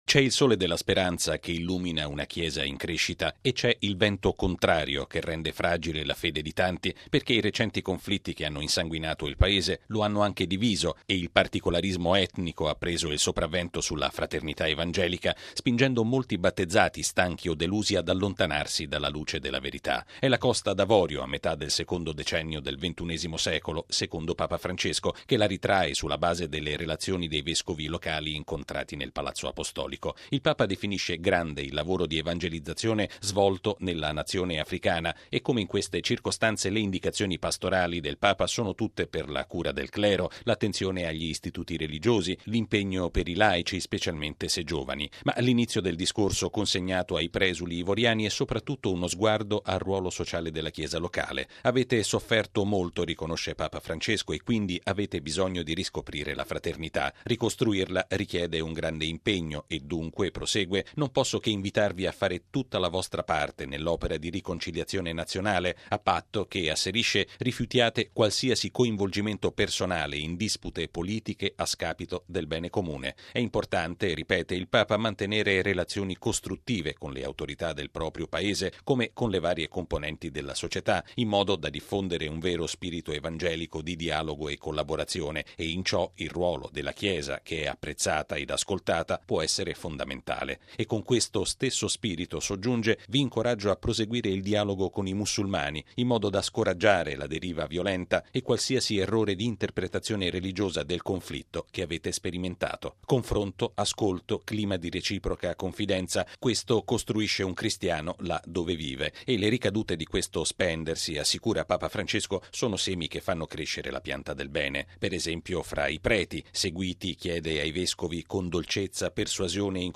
Il servizio